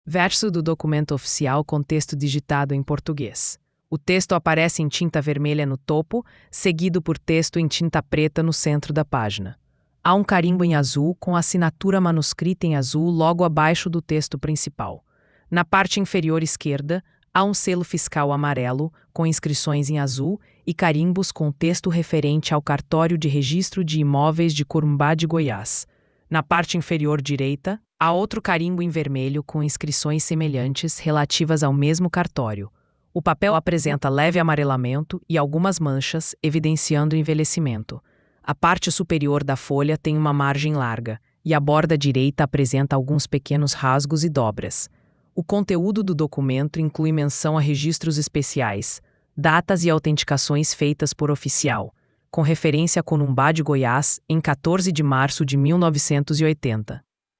QR code para acessar a audiodescrição da mídia Verso documento de doacao das terra de Ólhos d'Água